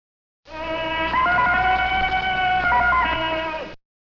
Zijn lievelingsuitdrukking is zijn snerpende junglekreet.
'Oehahoehaah!' Dan gaat er een siddering door de jungle.
tarzangeluid.mp3